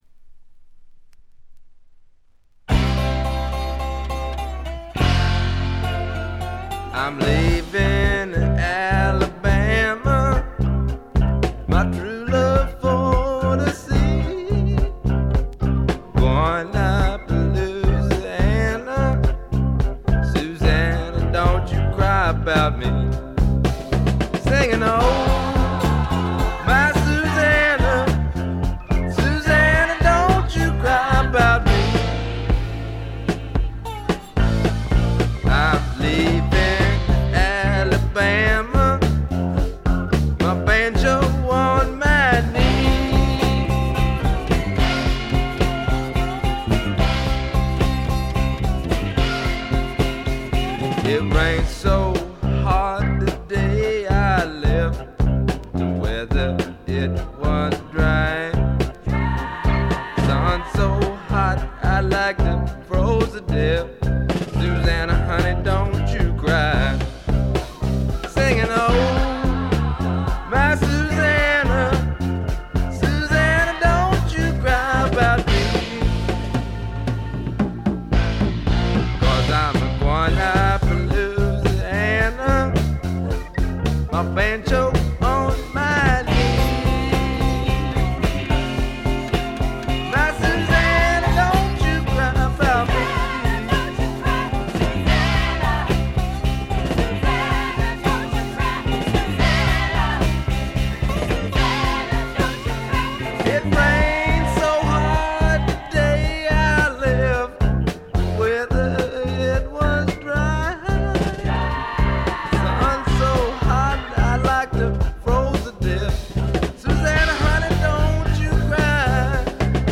ほとんどノイズ感無し。
いうまでもなく米国スワンプ基本中の基本。
試聴曲は現品からの取り込み音源です。